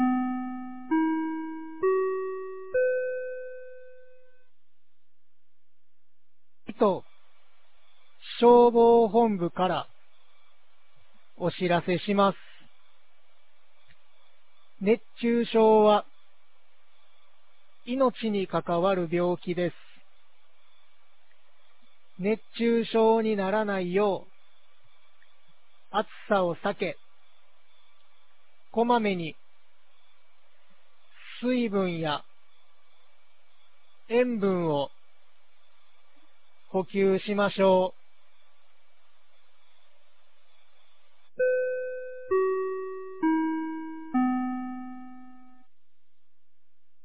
2023年07月03日 10時01分に、九度山町より全地区へ放送がありました。